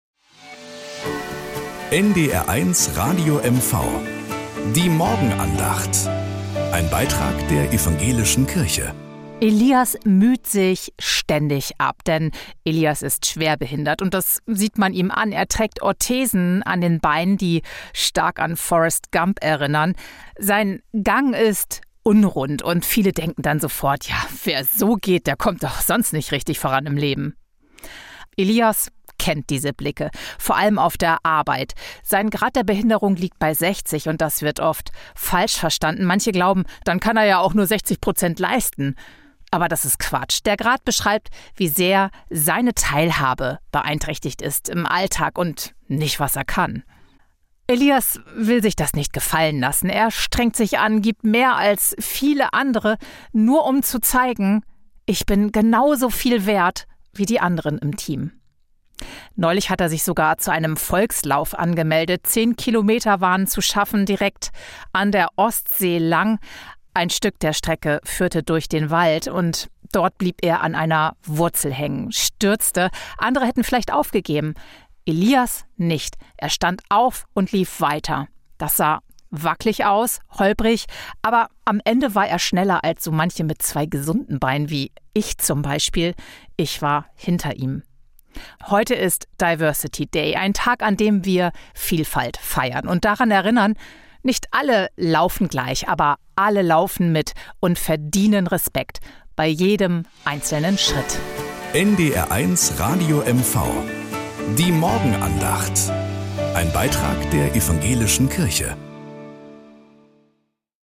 Morgenandacht auf NDR 1 Radio MV.